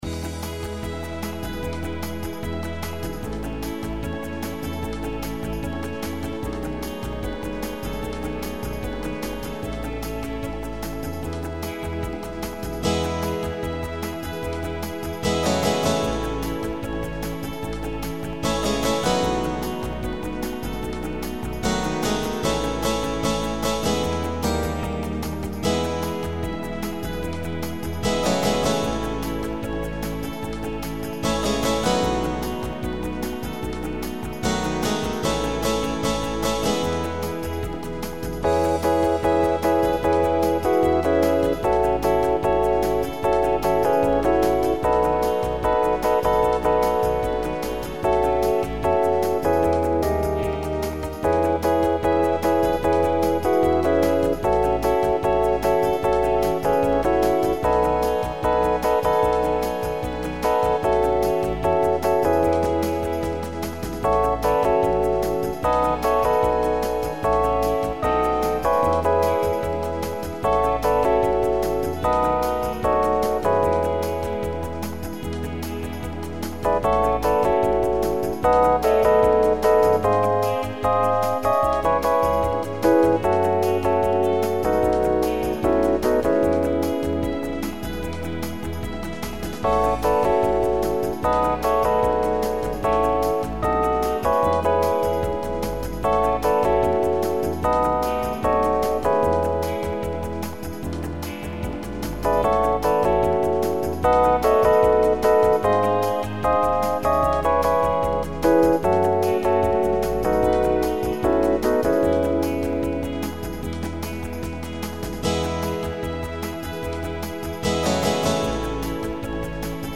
Recording from MIDI